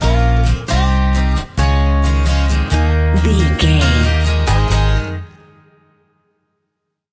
Aeolian/Minor
A♭
acoustic guitar
Pop Country
country rock
bluegrass
happy
uplifting
driving
high energy